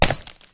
default_break_glass.1.ogg